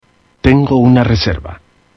Tags: Travel Pronunciation Basic Phrases Spanish Language International